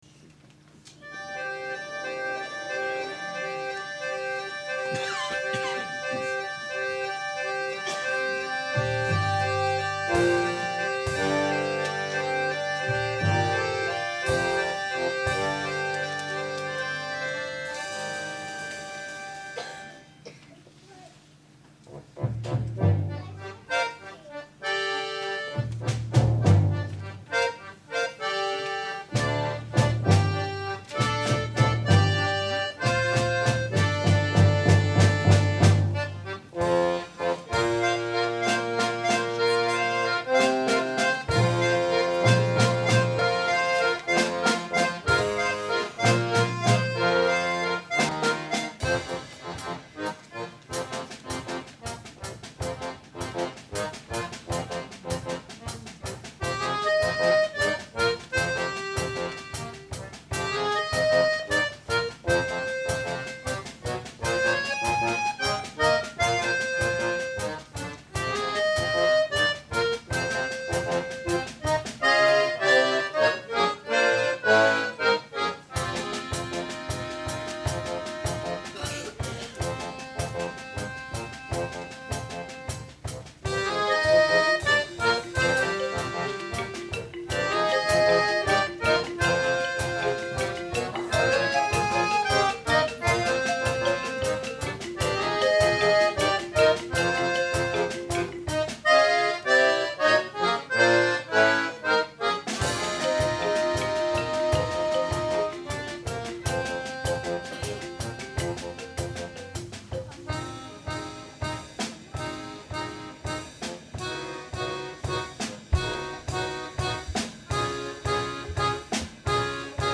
14.November 2004: Vereinskonzert
Jugendorchester